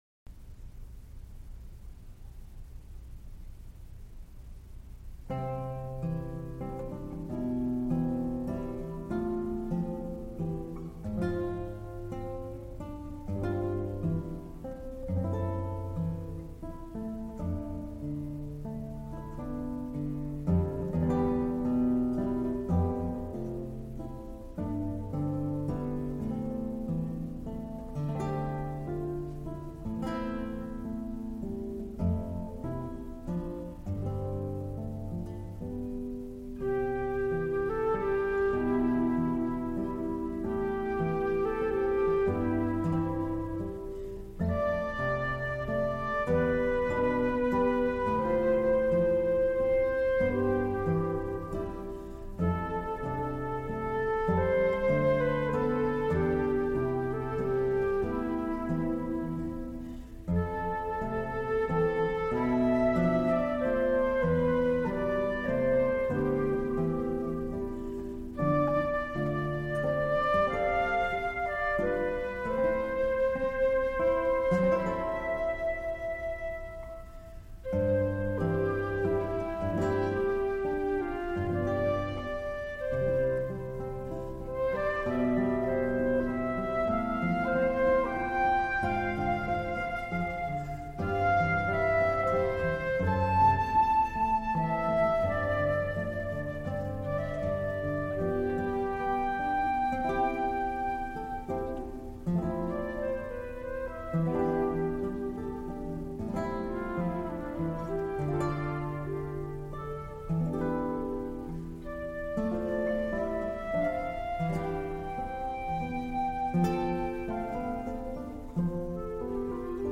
* Scored for flute & viola  /  ** Scored for flute & guitar